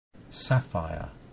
蓝宝石发音
英式发音：